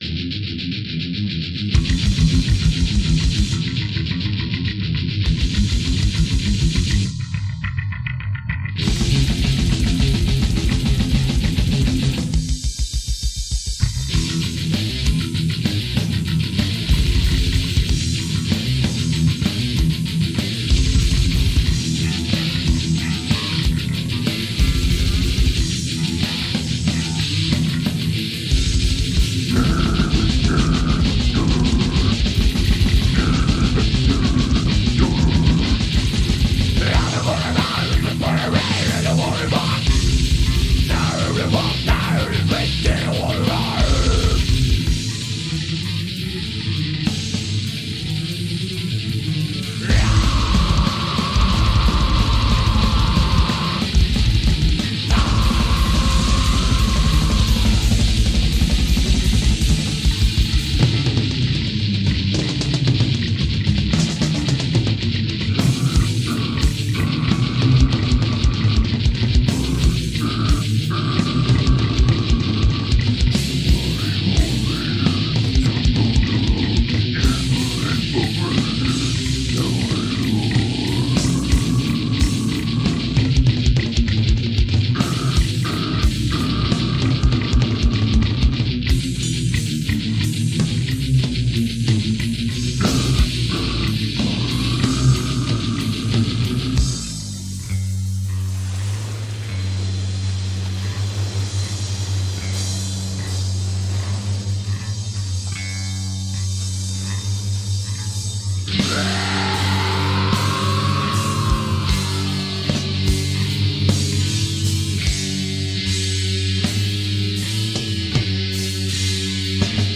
Genre: Death Metal